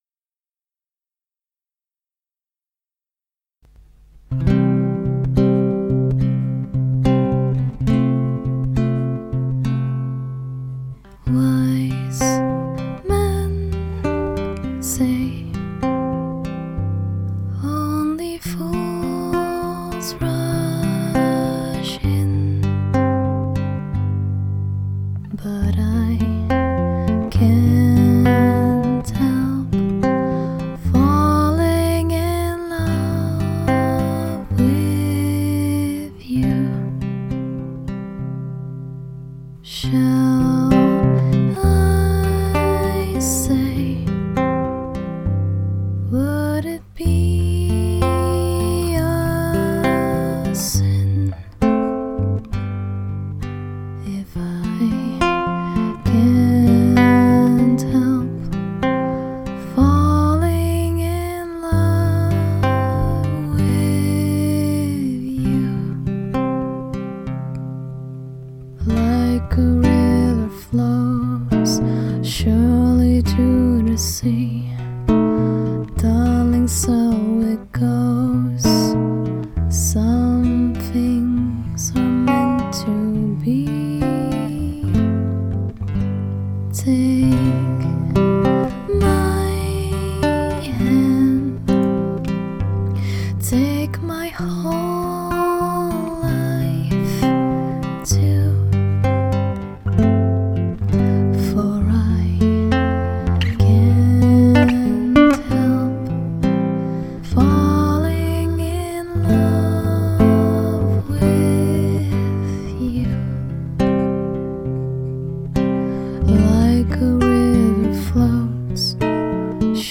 Voices are mine.
acoustic
Your voice is awe inspiring.
It fits your calm voice well.